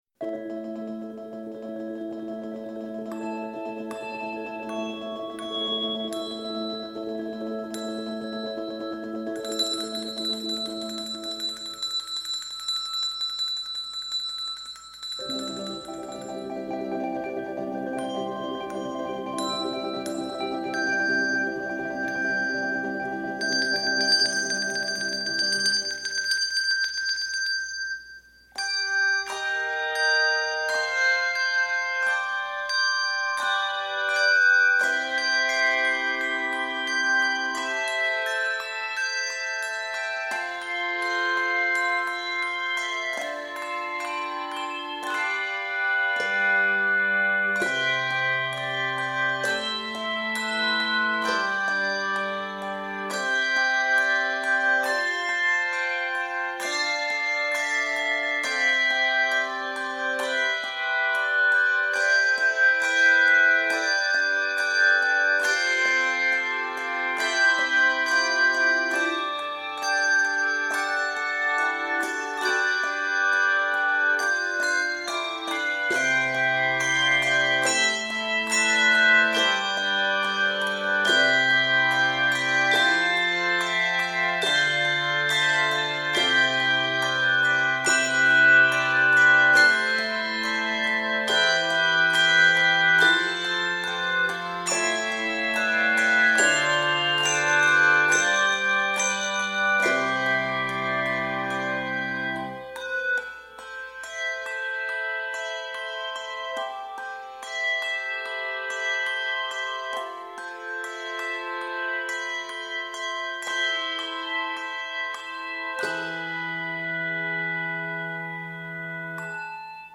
12-bell arrangements suitable for 3-6 ringers